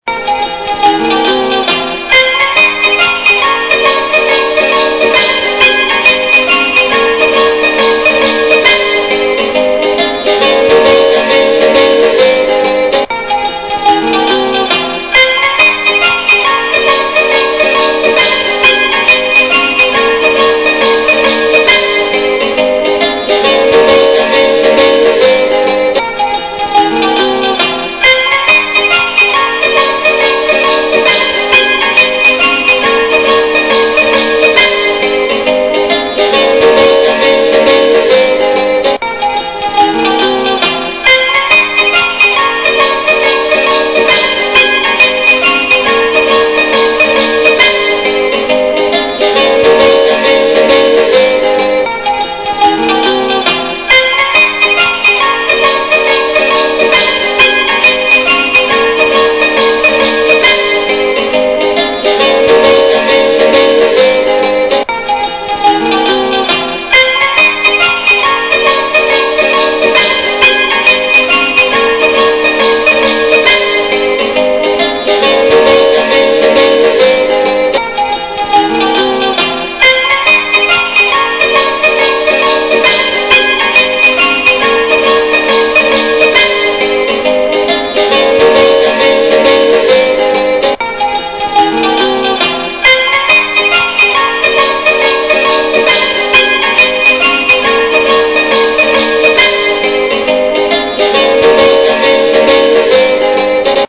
Tarantella.mp3